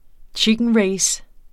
Udtale [ ˈtjigənˌɹεjs ]